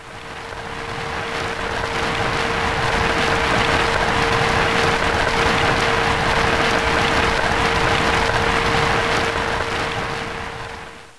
shower.wav